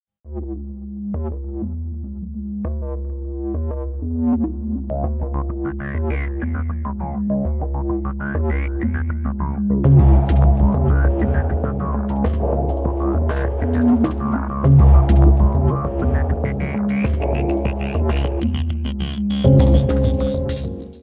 world ambient
voz
keyboards
bateria, percussão.
Área:  Pop / Rock